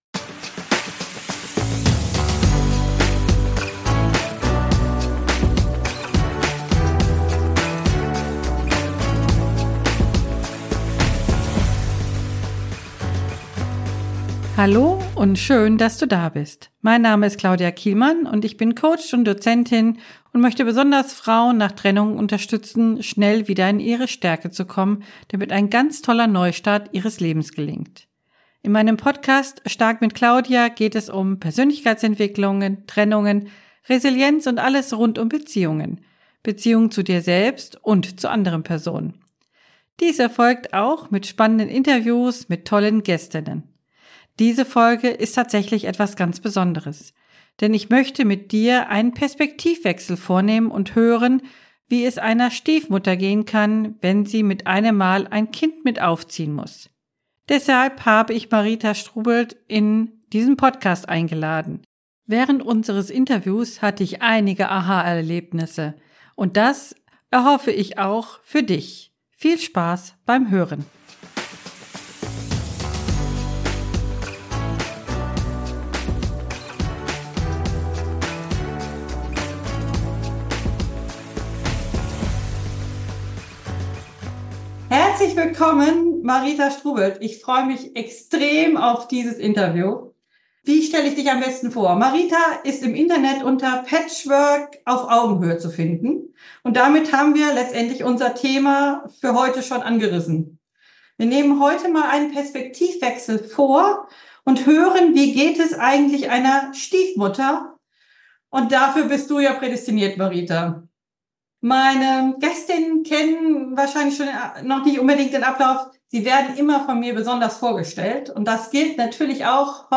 #045 Perspektivwechsel: Wie geht es einer „Stiefmutter“? - Interview